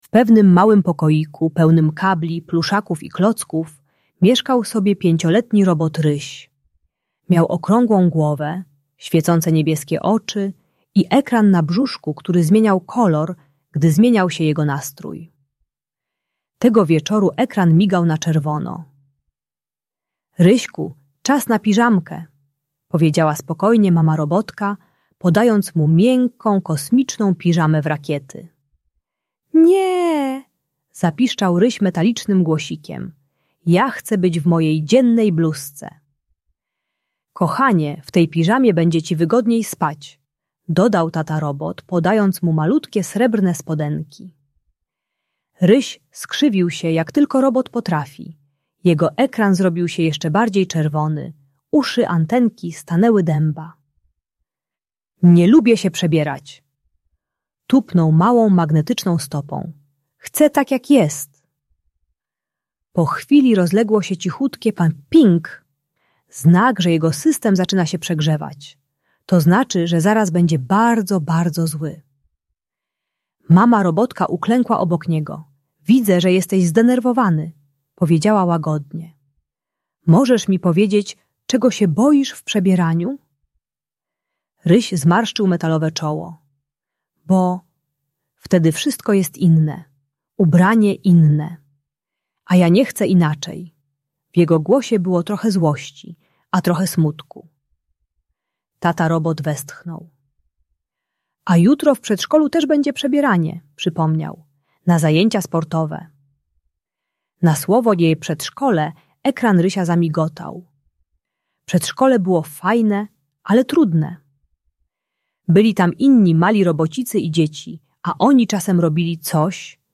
Audiobajka o radzeniu sobie ze zmianami uczy techniki głębokiego oddechu i akceptacji nowych sytuacji.